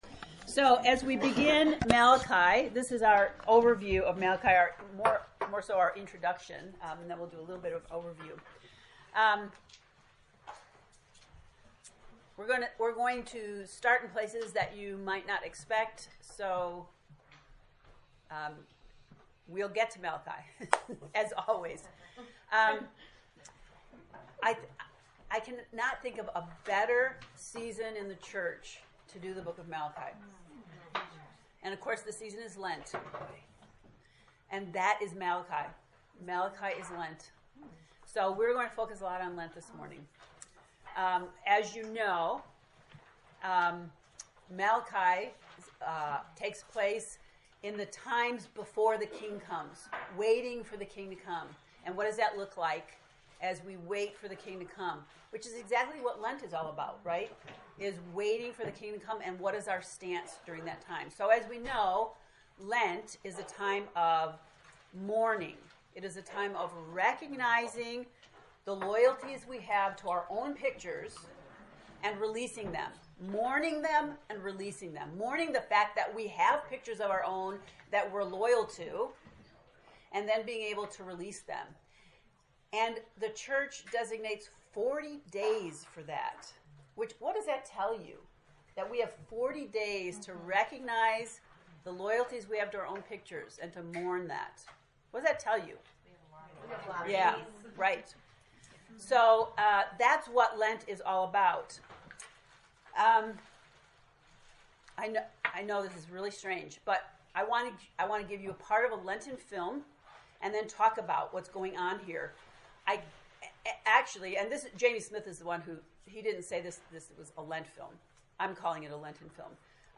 To listen to the lesson 0 lecture, click below: